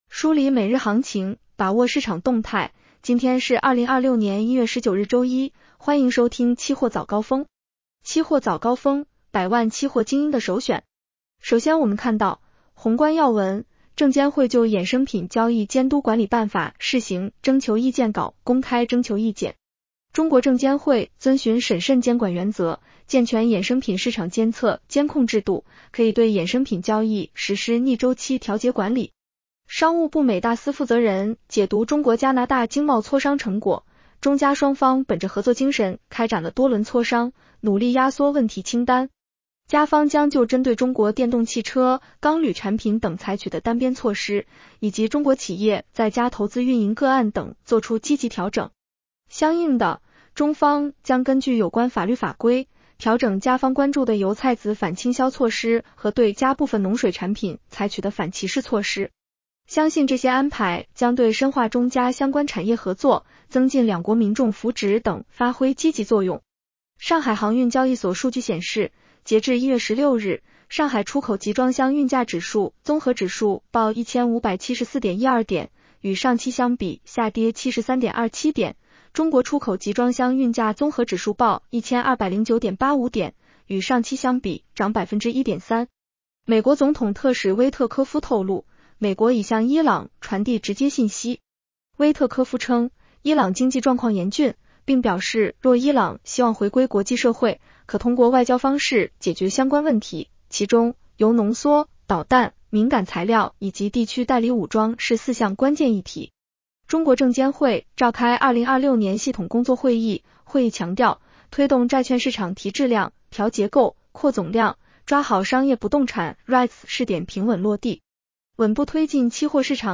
期货早高峰-音频版
期货早高峰-音频版 女声普通话版 下载mp3 热点导读 1.中方将根据有关法律法规，调整加方关注的油菜籽反倾销措施和对加部分农水产品采取的反歧视措施。